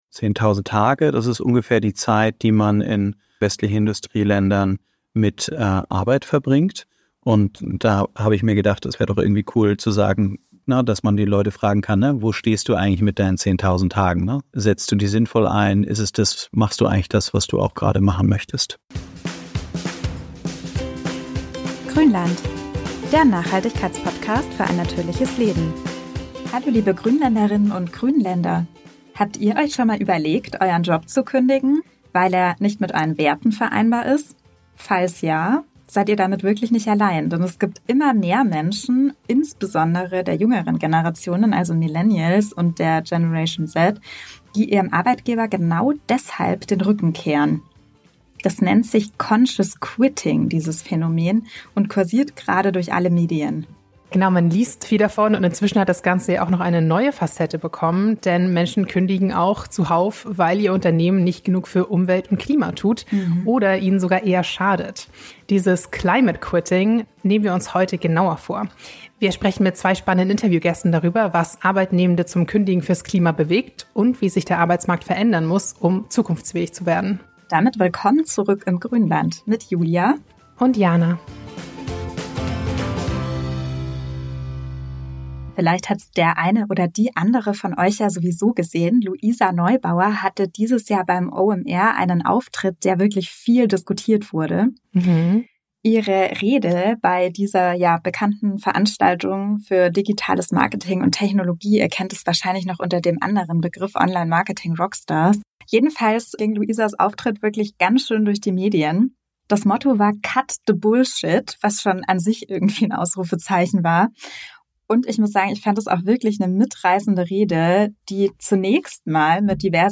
Und in welchen Branchen könnt ihr besonders viel zu einer lebenswerten Zukunft beitragen? Um diese und mehr Fragen zu beantworten, haben wir uns zwei spannende Menschen ins Grünland eingeladen.